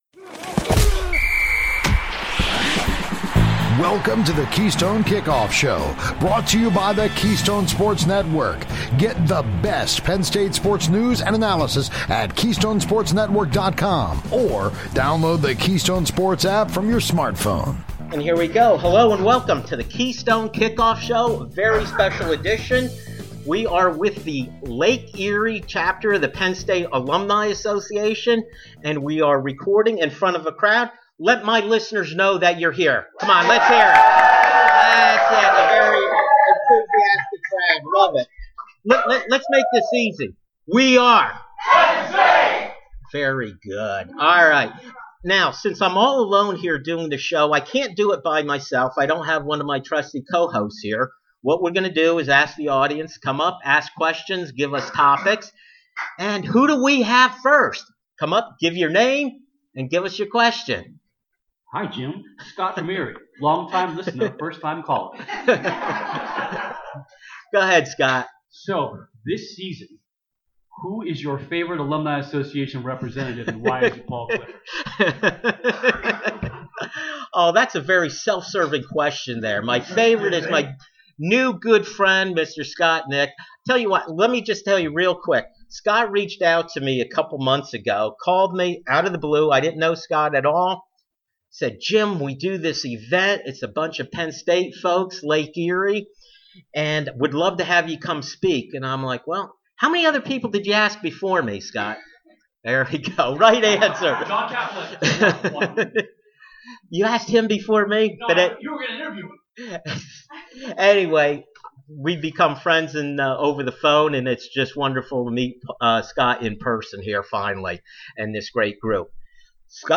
It was my great pleasure to join the Lake Erie Chapter of the Penn State Alumni Assoc for their annual BBQ. We recorded our show from there with the help of this tremendous group!